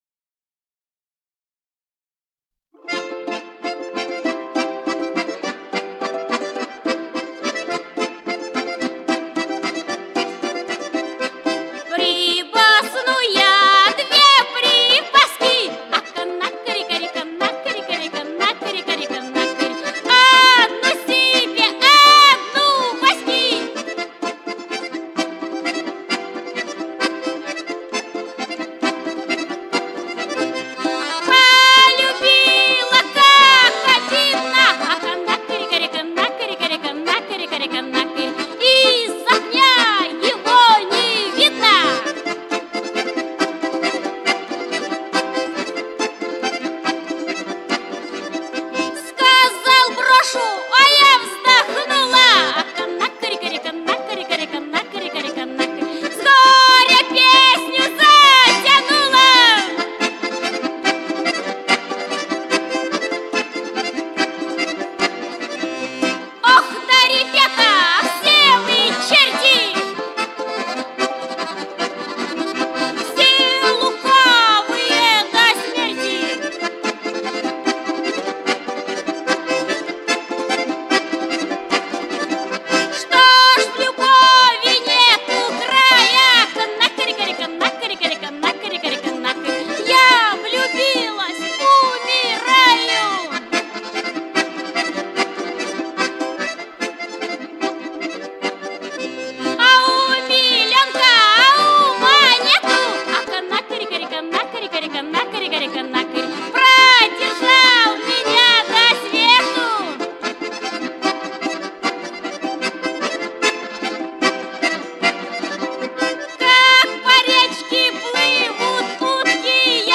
Прослушивание аудиозаписи прибасок-канырок в исполнении М. Мордасовой.
Прибаски.mp3